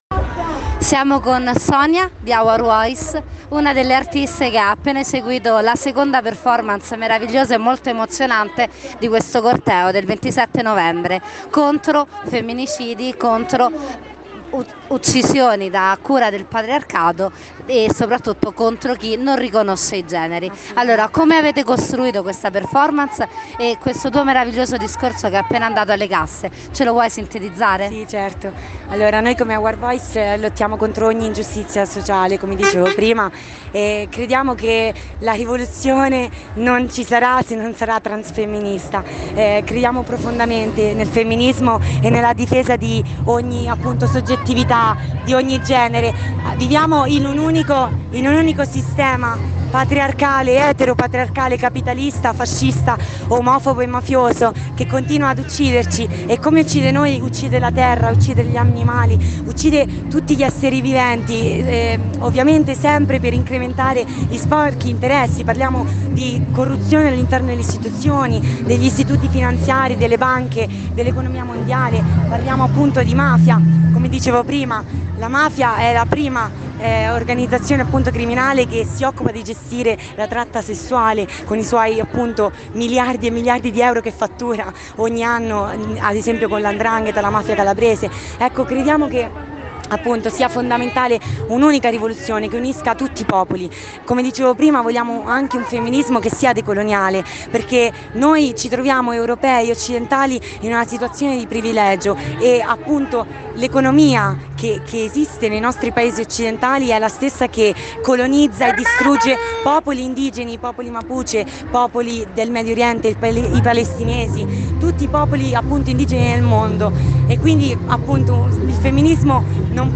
Manifestazione nazionale contro la violenza maschile sulle donne e di genere